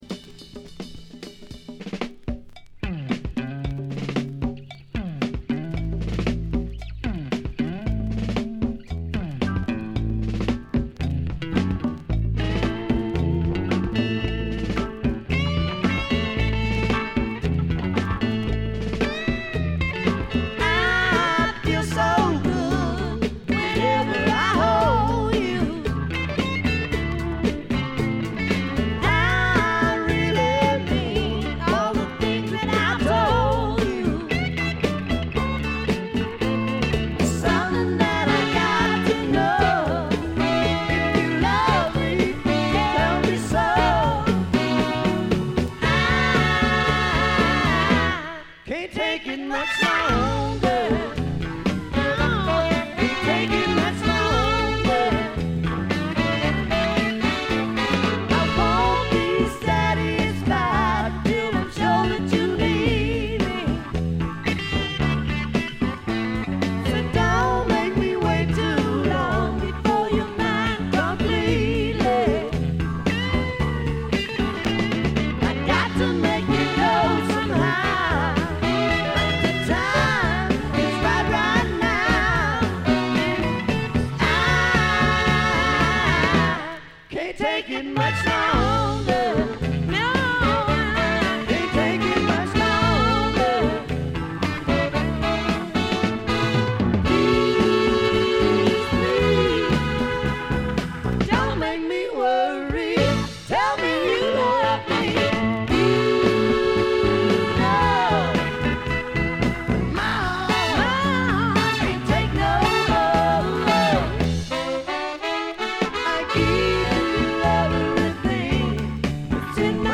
ところどころで軽微なチリプチ。
まさしくスワンプロックの原点ともいうべき基本中の基本盤。
試聴曲は現品からの取り込み音源です。